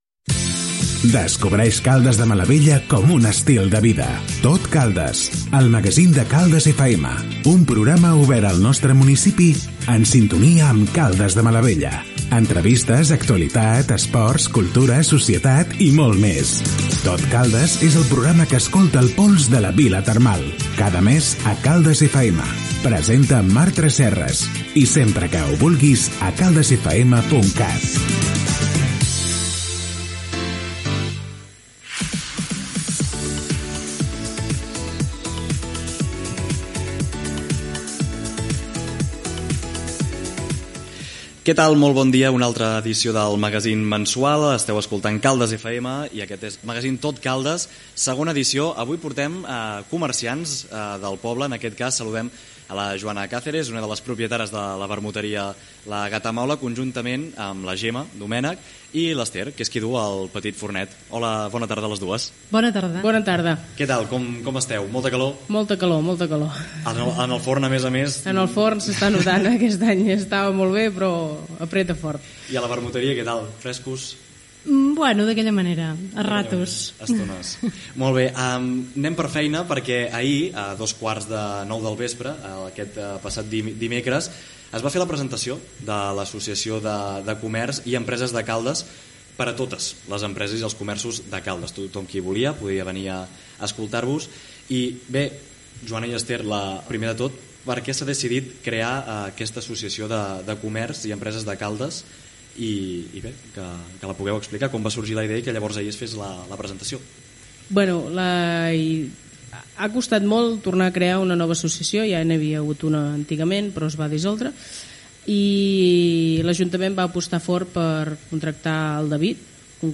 Careta del programa,presentació i fragment d'una tertúlia amb comerciants de Caldes de Malavella.